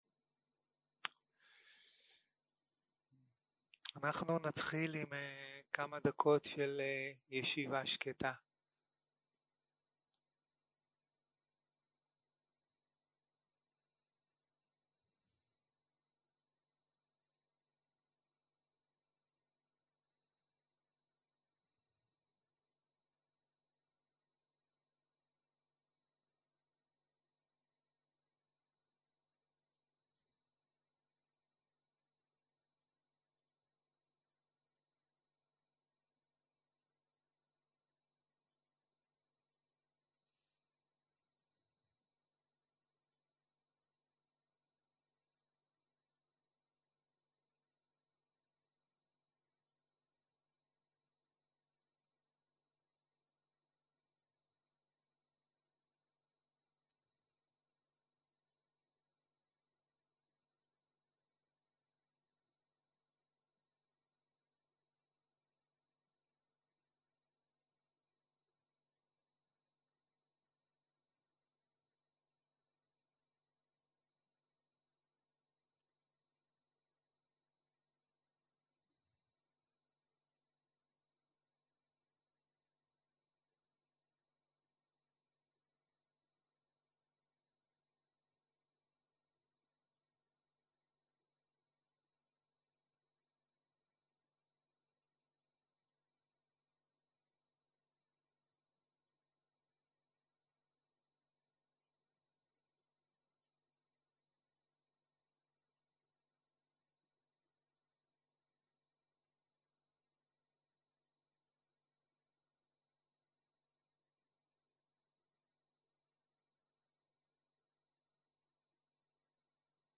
יום 3 - ערב - שיחת דהרמה - זאת רק דוקהה שמתהווה ומתפוגגת - הקלטה 7